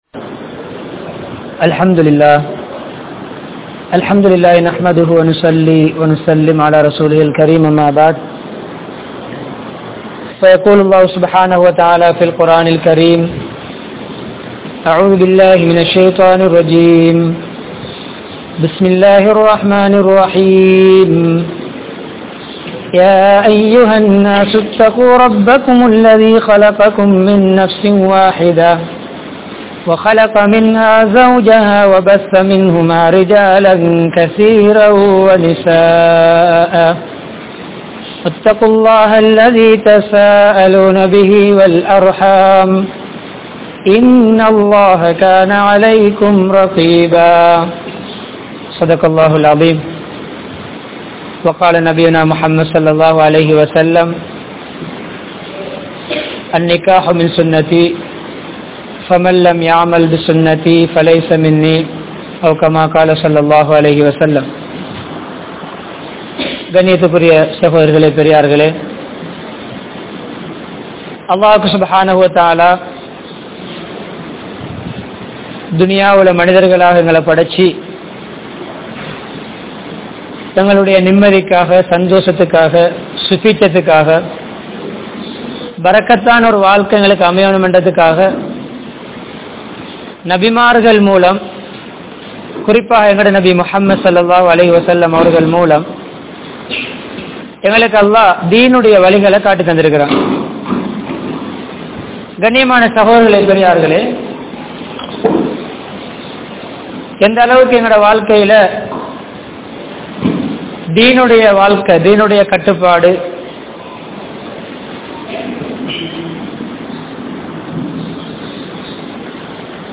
Kudumba Vaalkaiel Mahilchi Veanduma? (குடும்ப வாழ்க்கையில் மகிழ்ச்சி வேண்டுமா?) | Audio Bayans | All Ceylon Muslim Youth Community | Addalaichenai
Colombo 15, Crow Island, Noor Masjidh